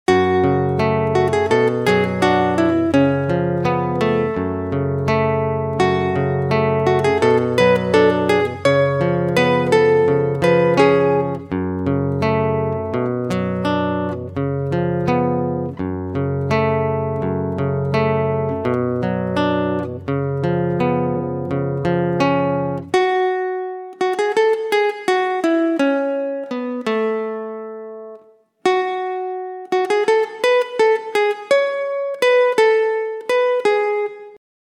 Latin
这个采样包涵盖了多种节奏和旋律，使得您可以很容易地为您的音乐中添加拉丁吉他的元素。
Latin guitar samples